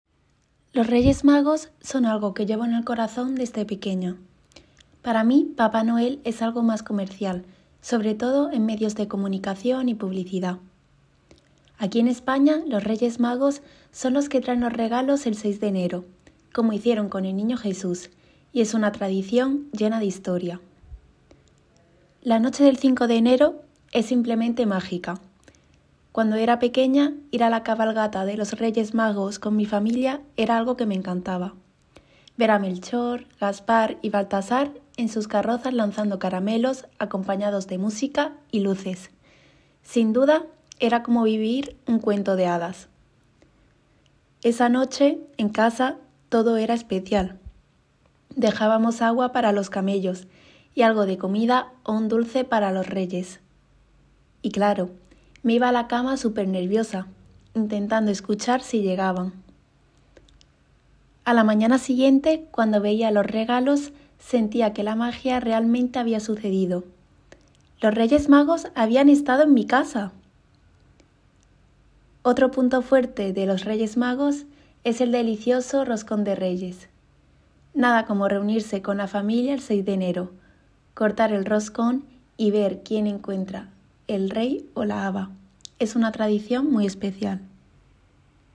Vous trouverez dans cette nouvelle rubrique de courts enregistrements réalisés par les assistants d’espagnol nommés dans notre académie, classés par thèmes et niveau du CECRL.